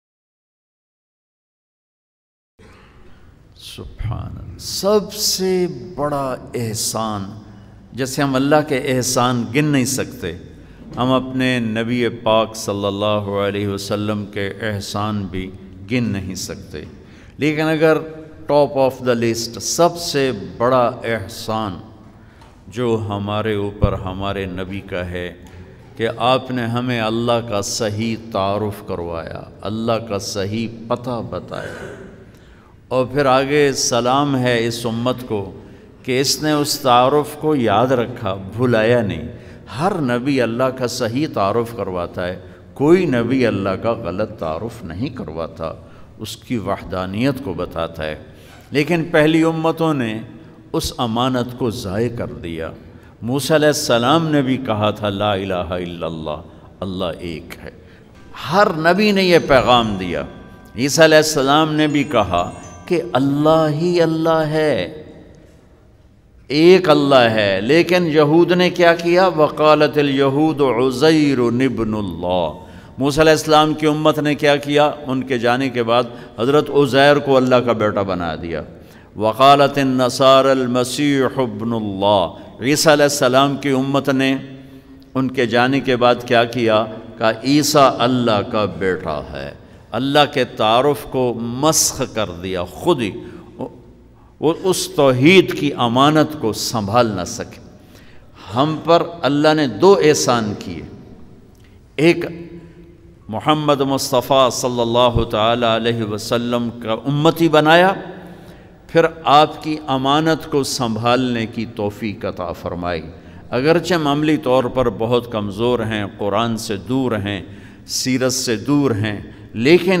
Aap (Saw) Ka Sab Se Bada Ahsan Molana Tariq Jameel Latest Bayan 18 August 2019 mp3.